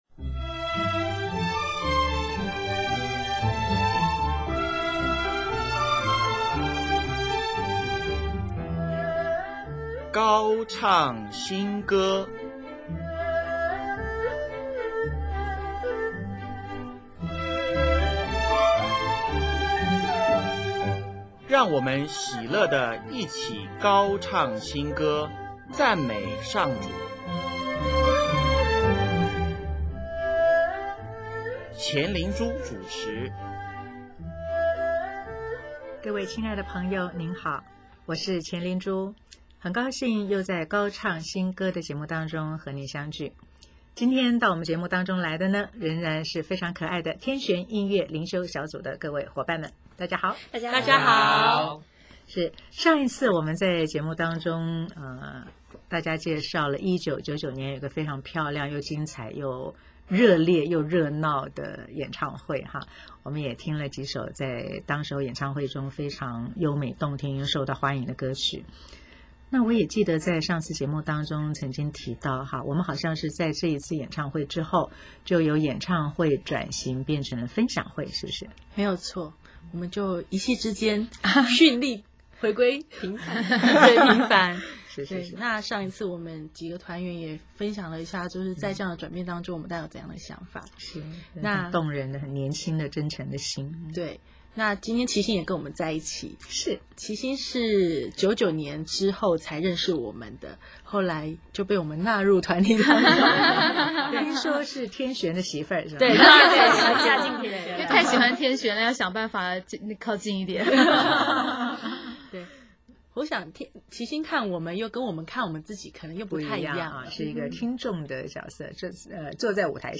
【高唱新歌】60|专访天旋音乐灵修小组(四)：月亮绕著太阳转